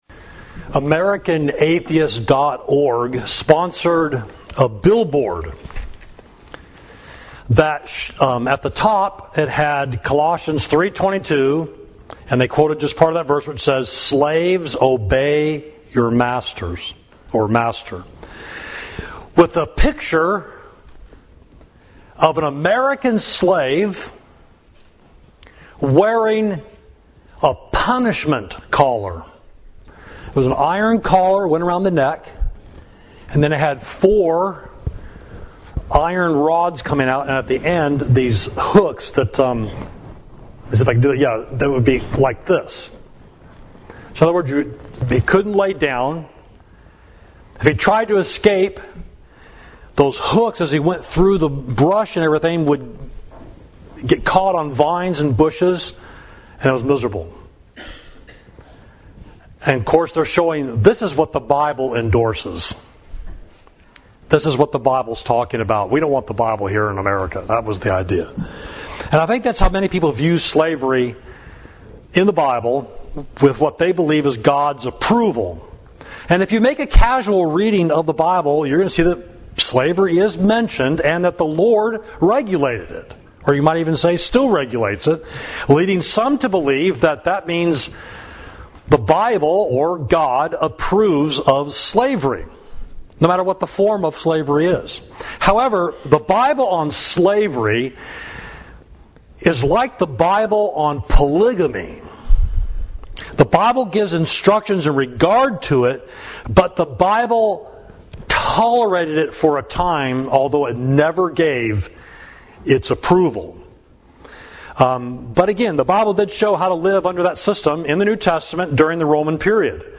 Sermon: Is the Bible Pro Slavery?
Listen to the sermon: [WordPress will not allow me to upload the PDF of my notes.